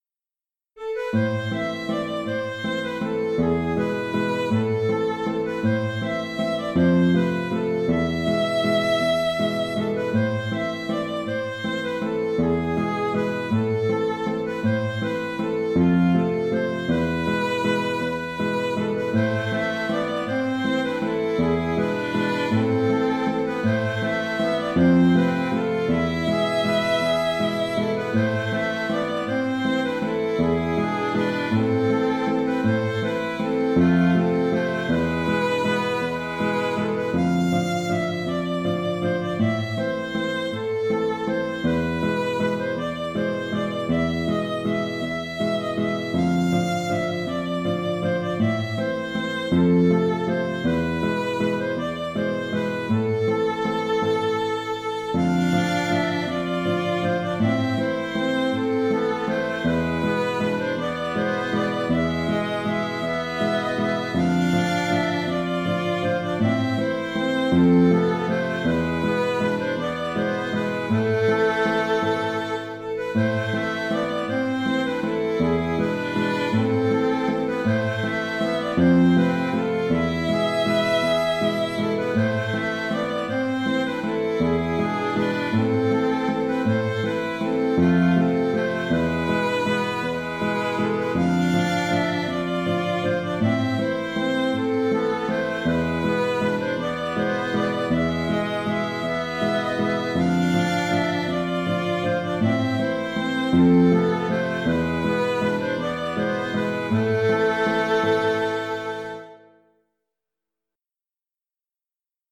Valse irlandaise (Valse) - Musique irlandaise et écossaise
Traditionnel irlandais faisant partie du répertoire du groupe irlandais bien connu The Dubliners. De tonalité mineure, il a comme particularité ses fins de phrase avec un accord VI en majeur (accord F en tonalité de Am), ce qui lui donne une couleur particulière.